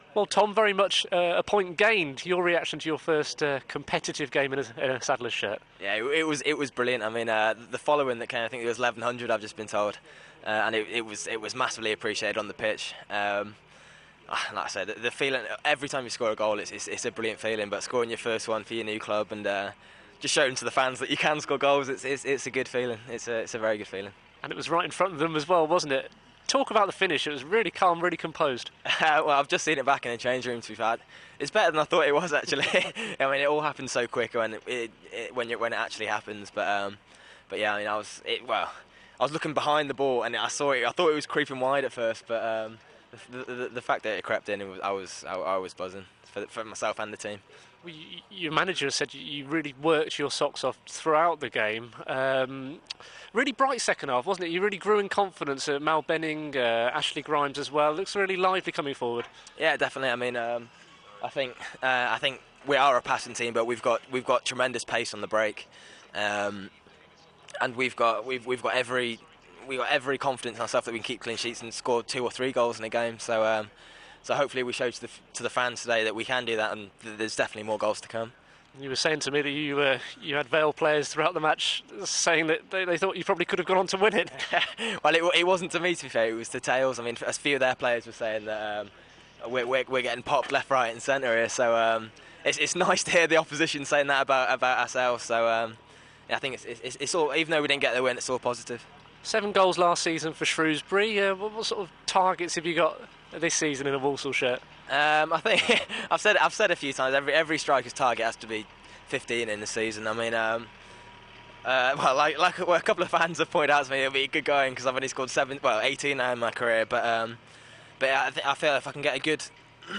Tom Bradshaw talks to BBC WM post Port Vale